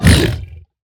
sounds / mob / hoglin / hurt4.ogg
hurt4.ogg